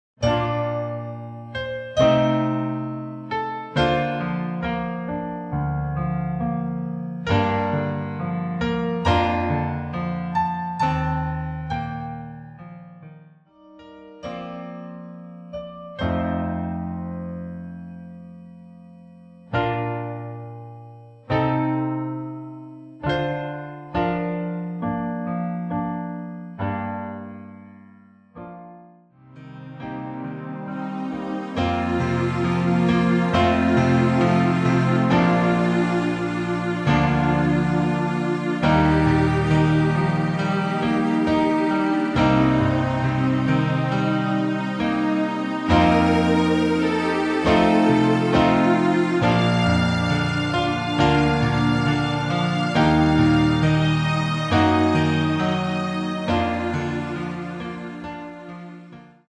-2)내린 피아노와 스트링만으로 편곡한 MR 입니다.
키 Bb